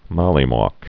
(mŏlē-môk)